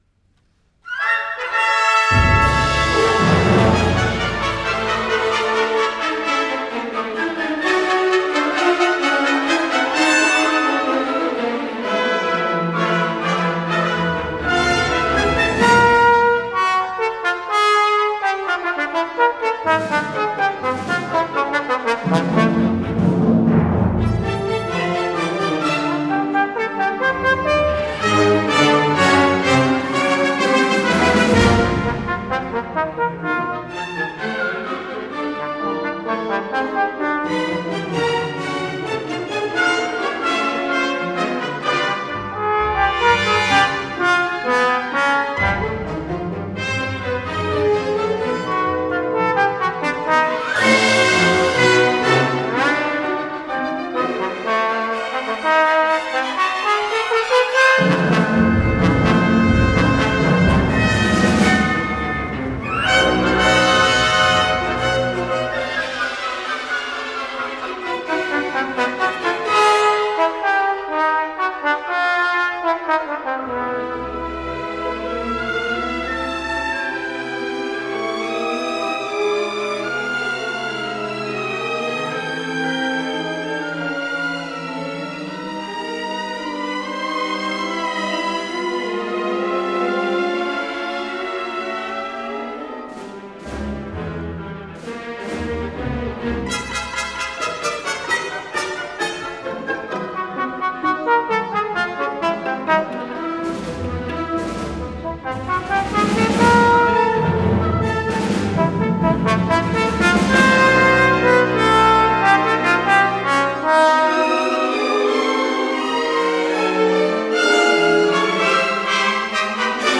Ernest Bloch: III. Allegro deciso